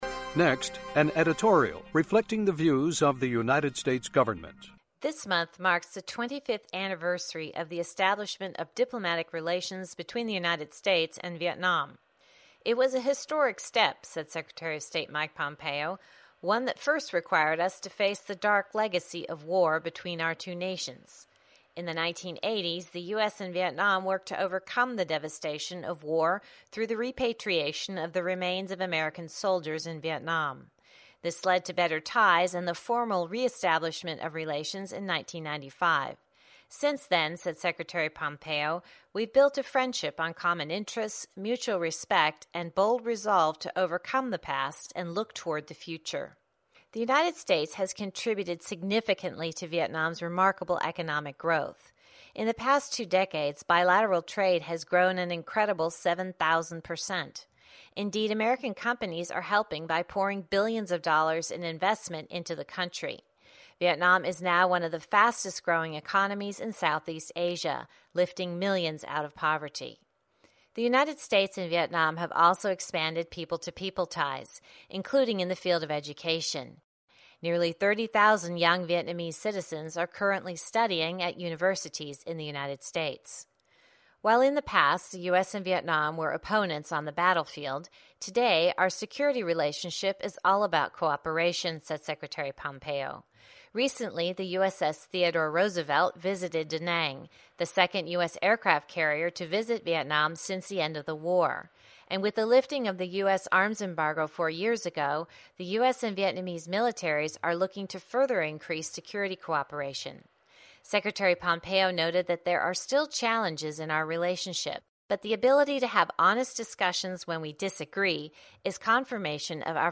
·On-line English TV ·English publication ·broadcasting station ·Classical movie ·Primary English study ·English grammar ·Commercial English ·Pronunciation ·Words ·Profession English ·Crazy English ·New concept English ·Profession English ·Free translation ·VOA News ·BBC World News ·CNN News ·CRI News ·English Songs ·English Movie ·English magazine